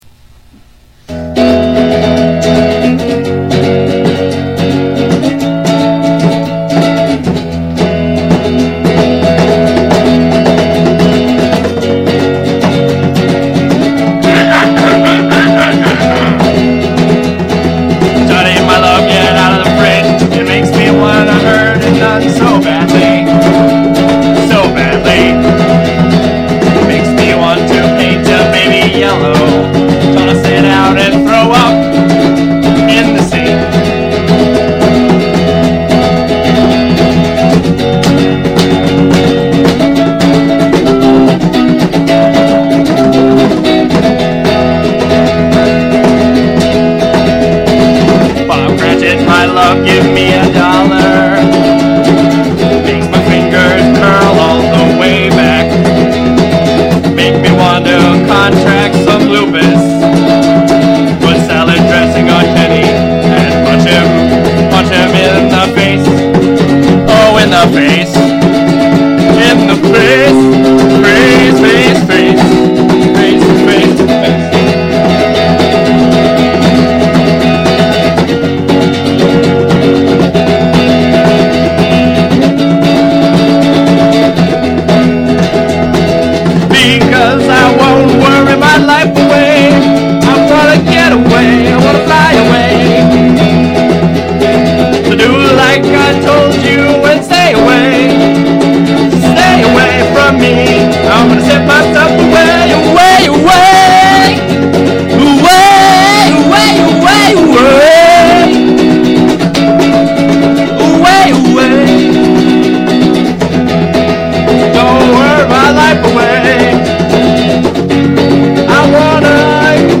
full of awesome covers and fourtrack excellence.
for fans of loud and/or fast-paced songs.